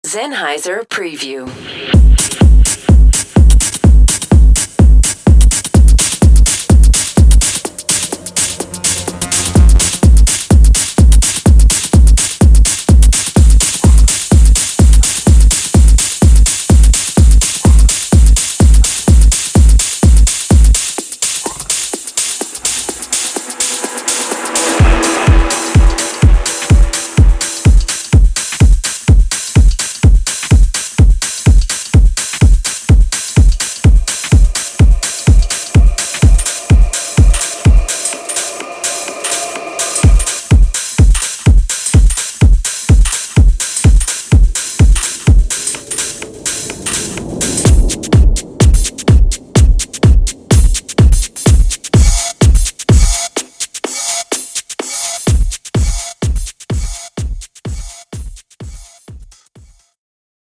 Techno
Perfectly suited for Techno, Minimal Techno, Progressive Techno, Tech House, House and all the classics. All the drum sounds have been created and designed from an array of classic analogue modules. drum machines and outboard kit whilst using today’s technology to master and refine.